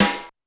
Drums_K4(05).wav